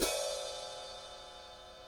Drum Samples
hat29.ogg